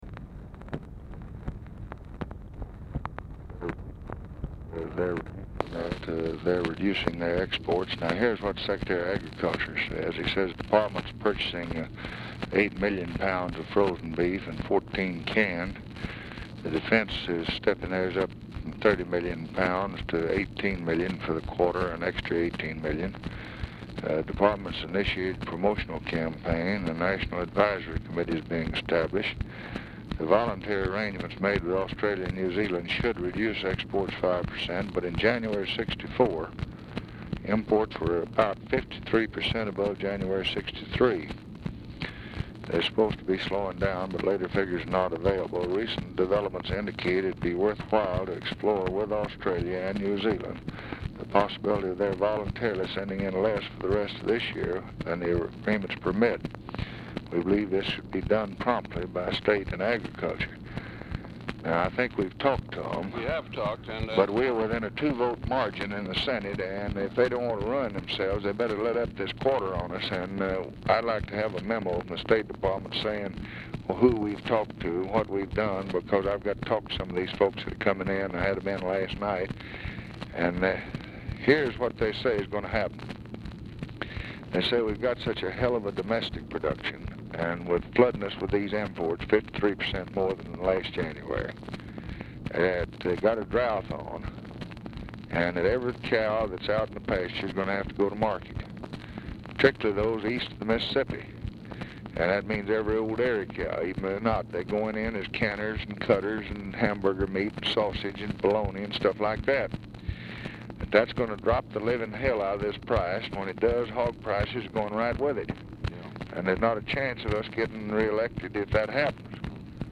BALL CONVERSATION RESUMES NEAR END OF RECORDING
Format Dictation belt
Location Of Speaker 1 Oval Office or unknown location
TELEPHONE OPERATORS, UNIDENTIFIED FEMALE
Specific Item Type Telephone conversation